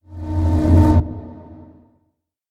Minecraft Version Minecraft Version snapshot Latest Release | Latest Snapshot snapshot / assets / minecraft / sounds / mob / zombie / unfect.ogg Compare With Compare With Latest Release | Latest Snapshot